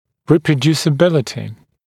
[ˌriːprəˌdjuːsə’bɪlətɪ] [ˌри:прэˌдйу:сэ’билэти] воспроизводимость, повторяемость